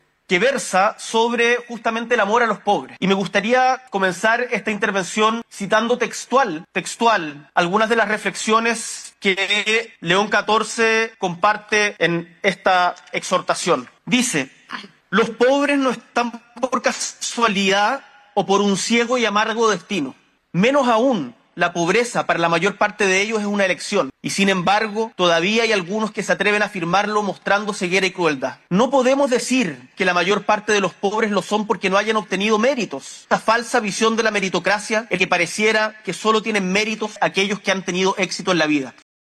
El Presidente Gabriel Boric inauguró este martes la XLVII edición del Encuentro Nacional de la Empresa (ENADE 2025), organizado por el Instituto Chileno de Administración Racional de Empresas (ICARE), donde hizo un llamado a la unidad y a la construcción de un país más justo y solidario, citando reflexiones del papa León XIV, con quien se reunió el lunes en el Vaticano.